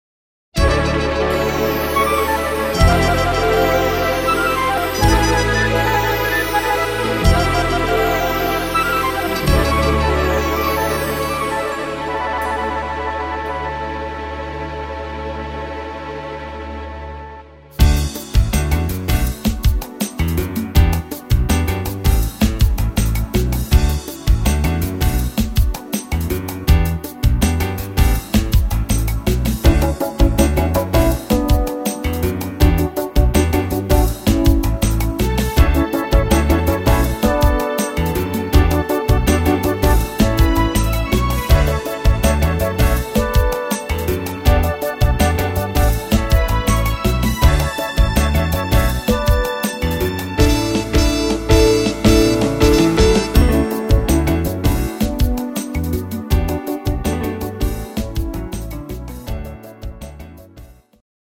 Instrumental Gitarre